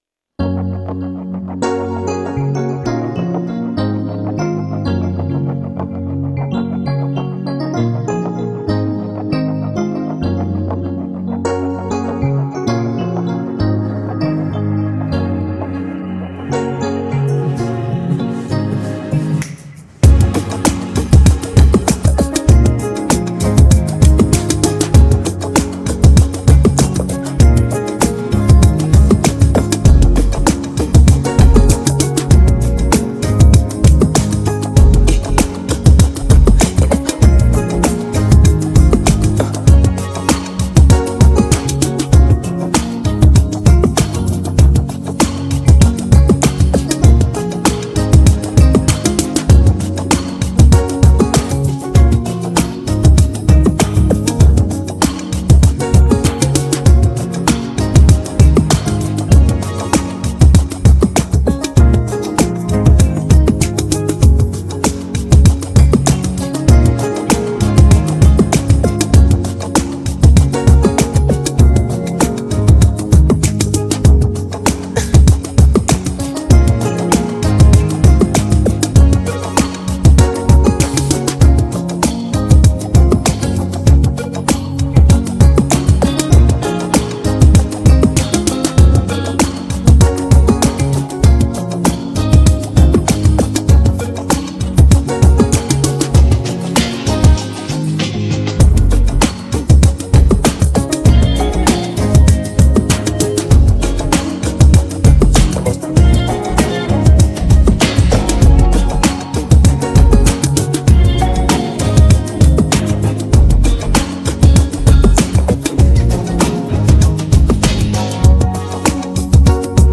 Pop music